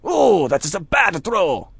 mp_badthrow.wav